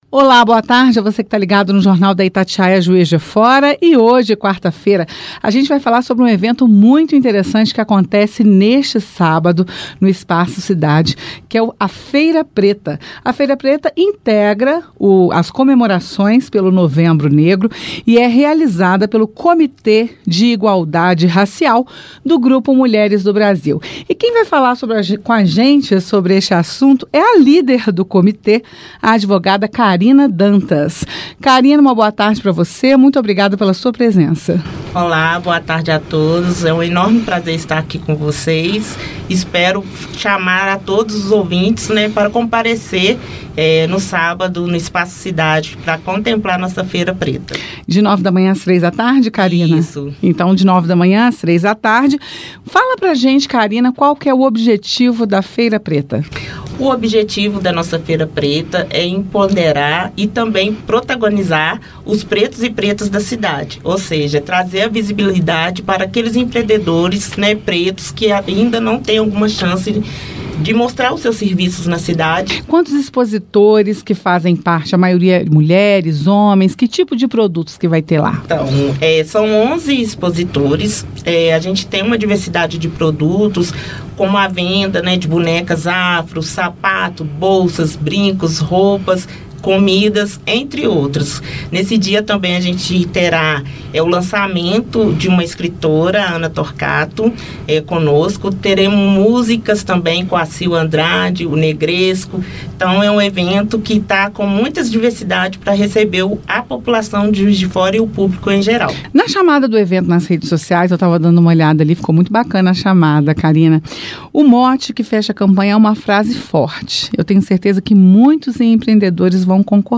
Feira-Preta-Itatiaia-Entrevista-jf-juiz-de-fora.mp3